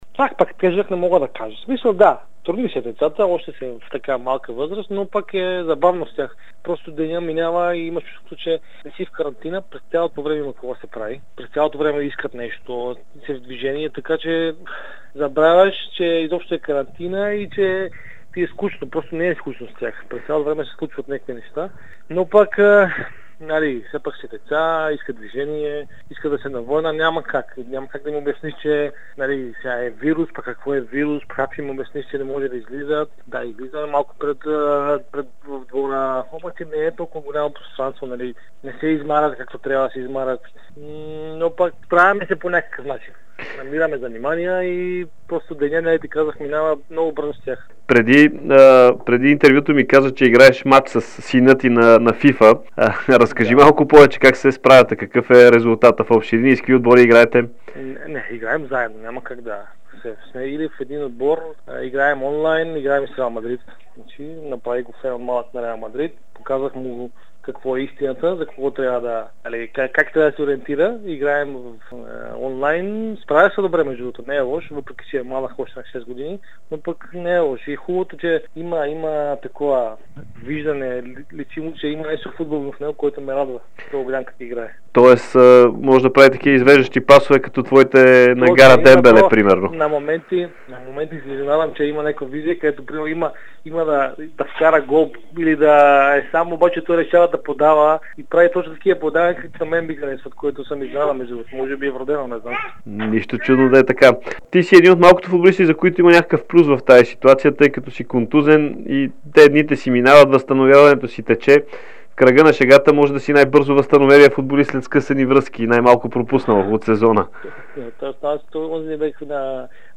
Дарко Тасевски даде специално интервю за Дарик радио и dsport, в което разказа за ежедневието си по време на пандемията от COVID-19. Той разкри някои интересни случки с младите футболисти във Славия и заяви своите планове да продължи с футбола като треньор. Северномакедонецът разкри, че нищо не му пречи в бъдеще да застане начело на Левски, където като играч изживя най-добрите моменти в кариерата си.